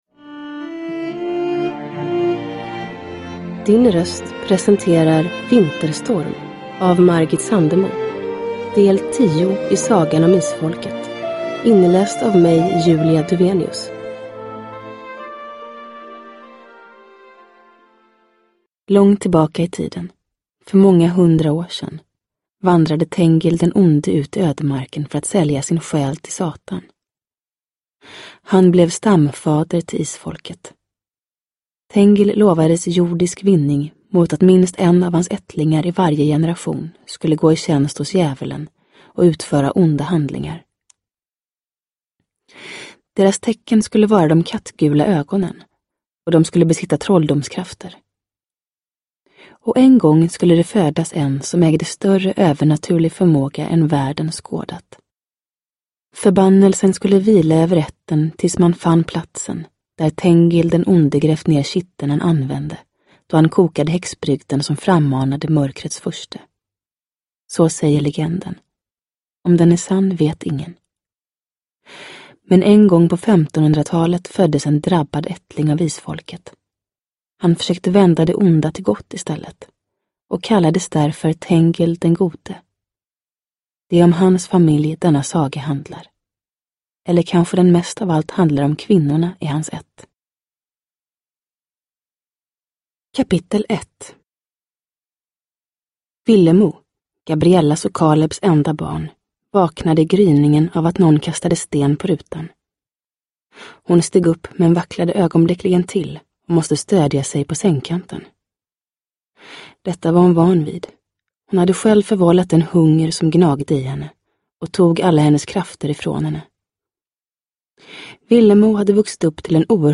Vinterstorm – Ljudbok
Sagan om Isfolket, nu äntligen som ljudbok. I suverän ny inläsning av Julia Dufvenius.
Uppläsare: Julia Dufvenius